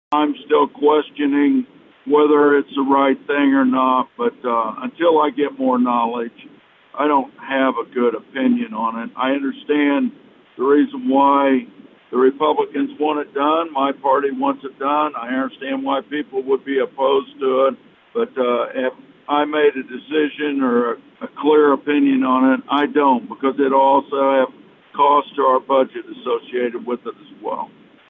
Ahead of the session, Black gave his thoughts on redistricting.